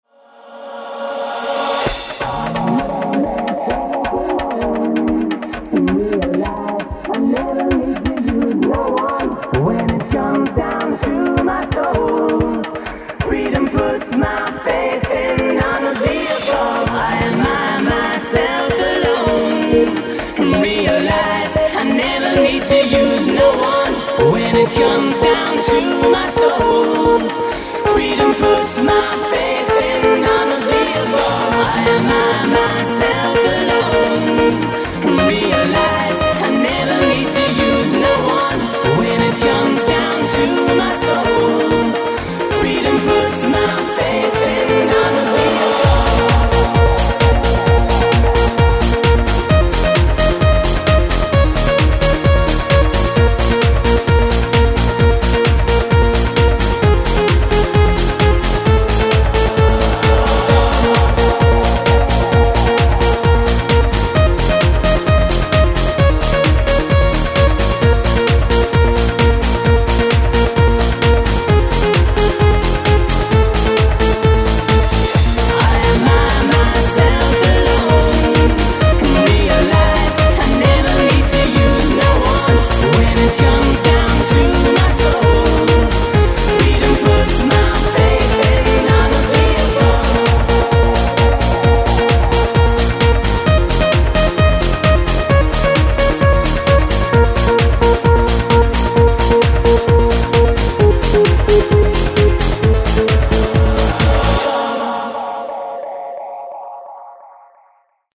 1731,96 KB ci ho fatto sopra un bel "tunz".
suona da paura